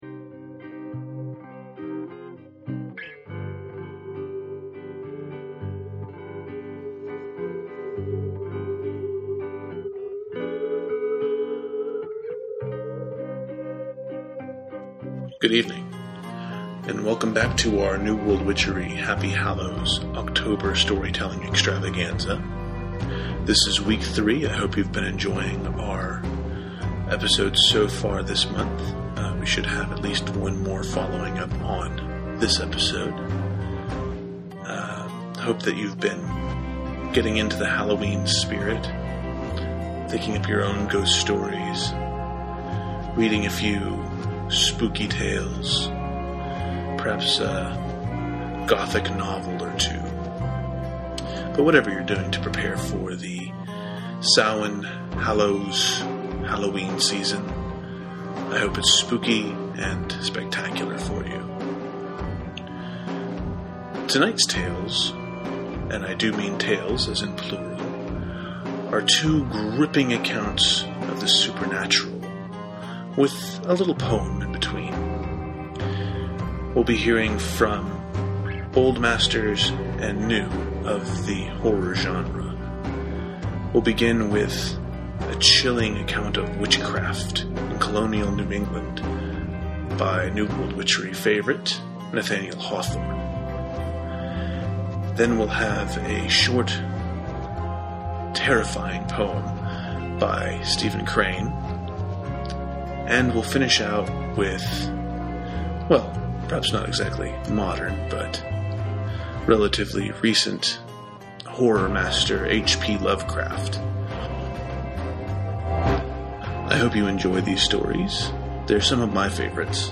storytelling